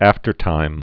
(ăftər-tīm)